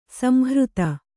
♪ samhřta